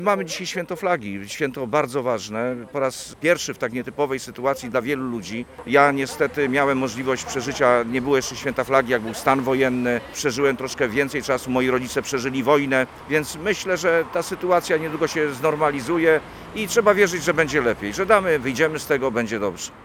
Mówi Marek Paprocki , przewodniczący Rady Powiatu Mieleckiego